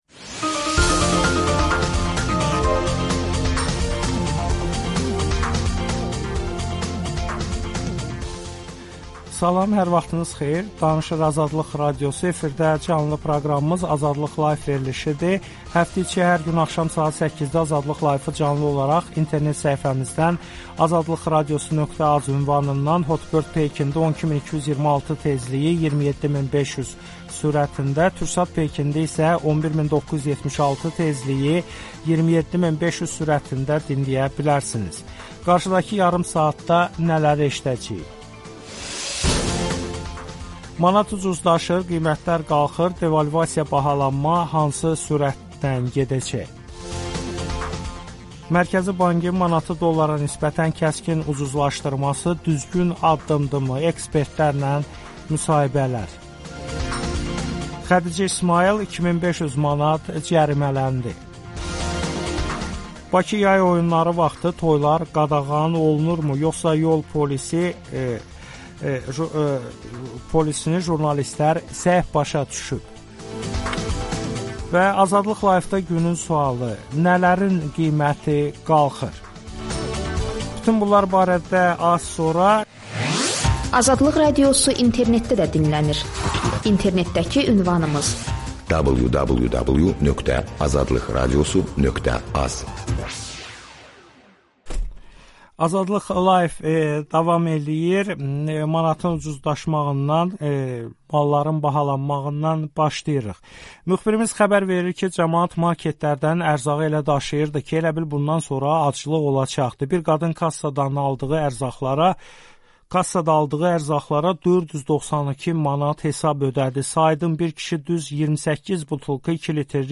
AzadliqRadiosunun AzadliqLive proqramı hər gün axşam saat 8-də Azərbaycanda və dünyada baş verən əsas hadisələrin mənzərəsini yaradır. 24 saatın axarı 30 dəqiqəlik qəlibdə - qısa, lakin maraqlı reportajlar, şərhlər, müsahibələr